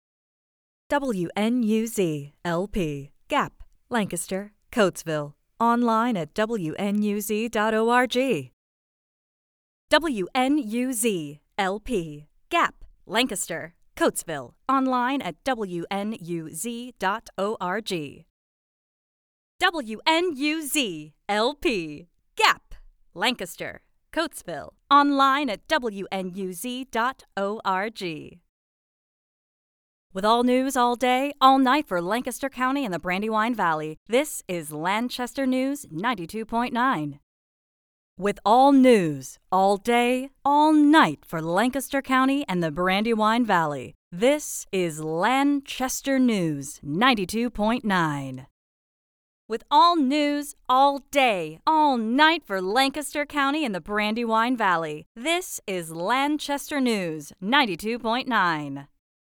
Radio Imaging
RADIO IMAGING SAMPLE.mp3